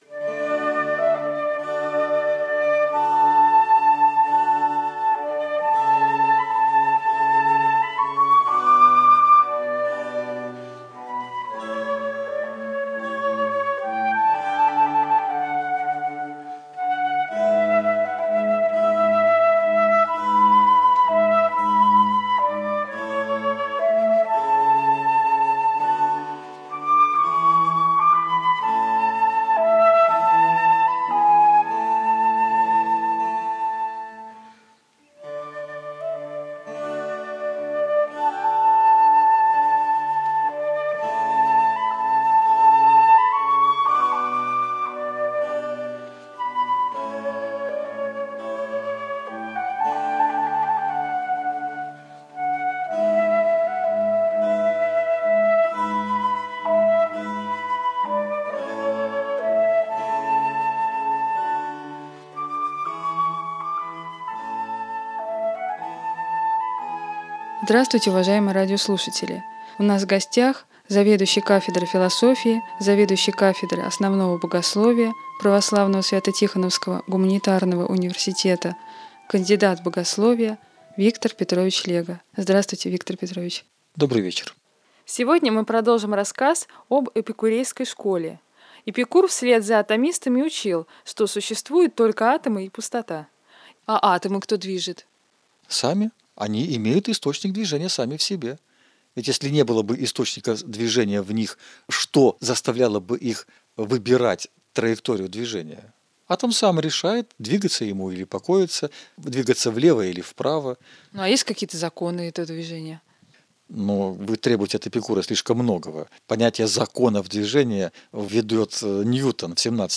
Цикл бесед на тему «История философии».